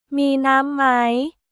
ミー・ナーム・マイ